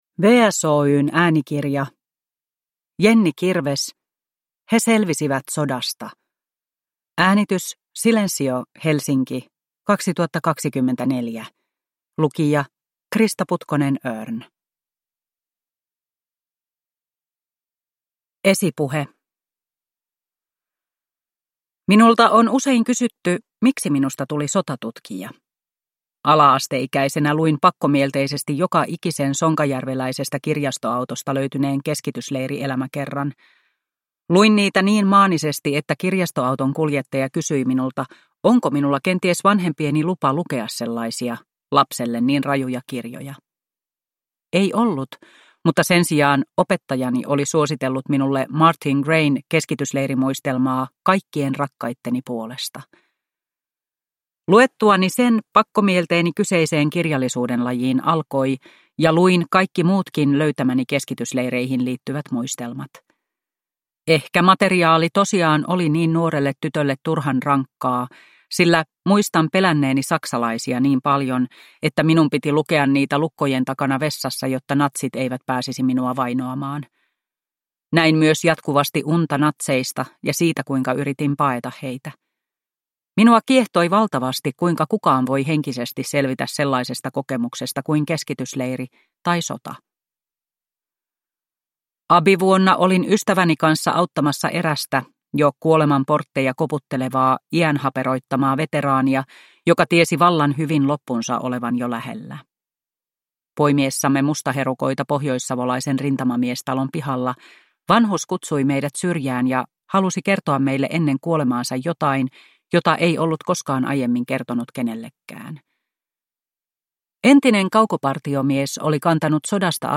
He selvisivät sodasta – Ljudbok